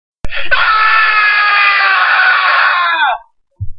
AAAAHHHHH!